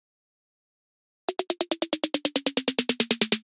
Скатывание переход